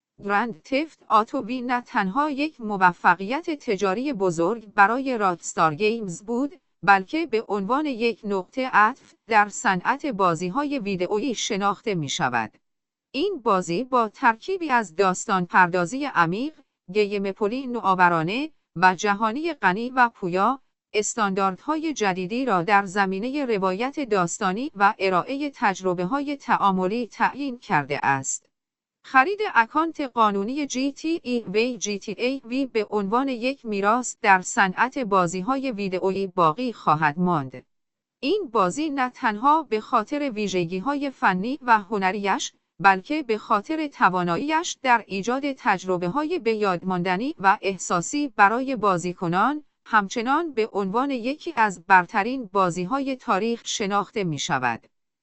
صوت-خلاصه-محتوا-gta-v.ogg